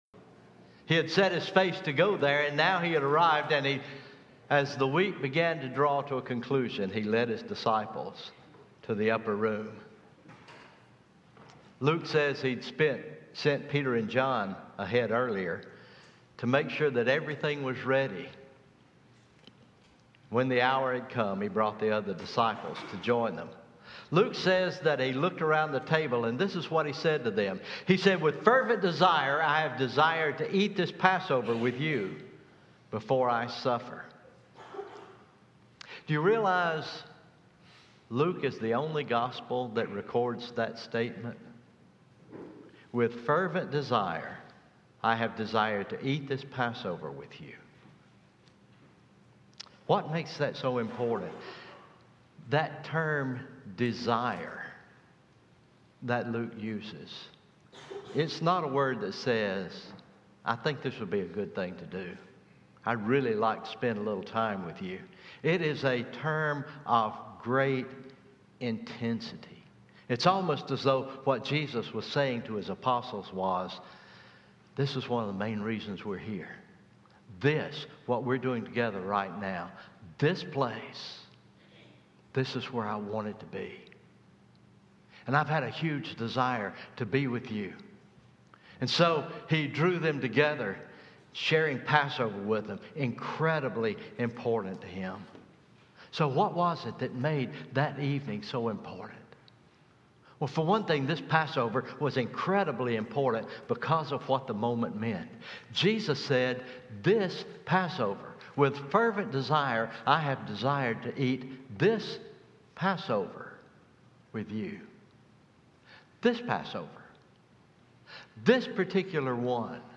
Communion Meditation